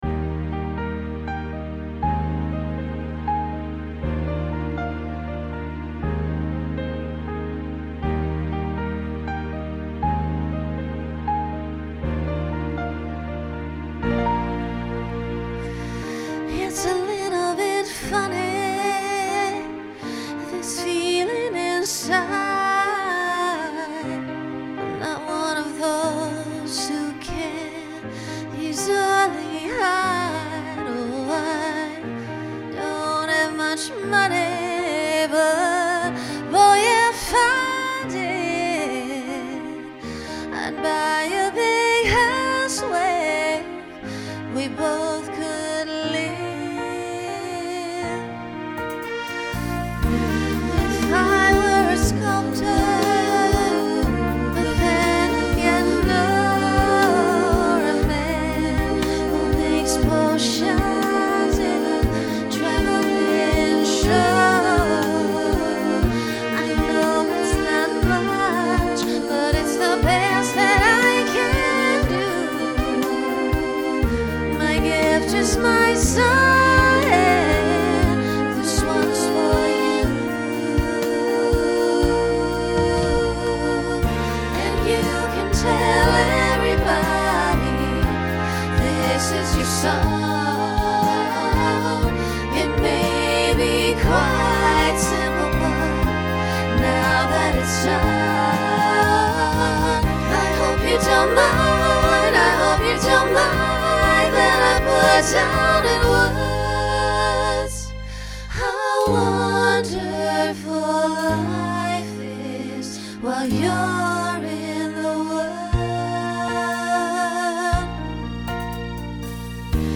Voicing SSA Instrumental combo Genre Pop/Dance
Ballad